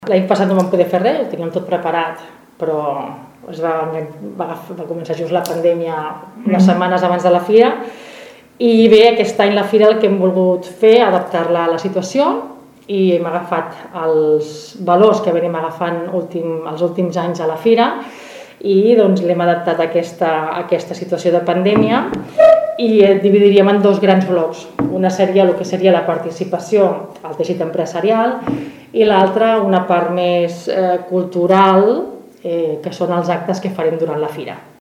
Ho explica la regidora de Promoció Econòmica, Nàdia Cantero.